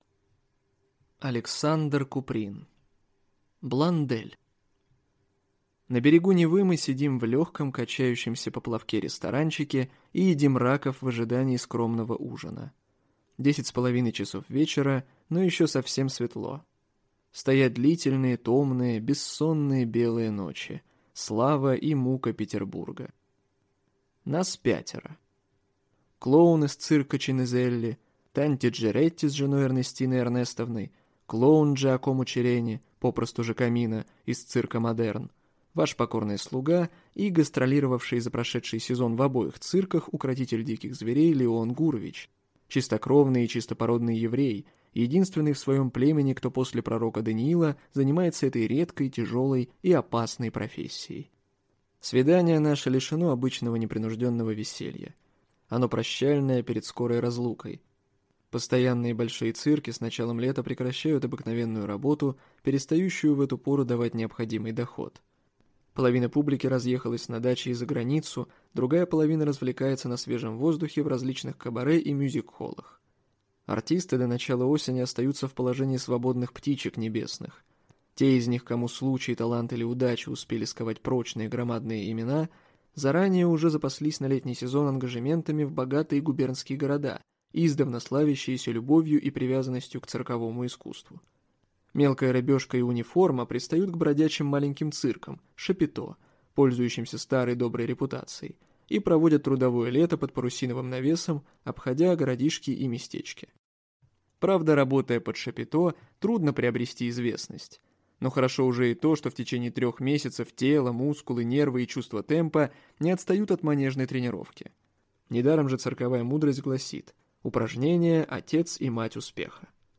Аудиокнига Блондель | Библиотека аудиокниг